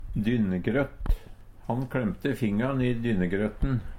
dynnegrøtt - Numedalsmål (en-US)